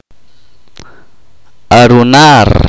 [ʔak.’p’i maʔ.’ʧ͡i ax.’ni e ʧ͡’i’] oracion yesterday the dog did not run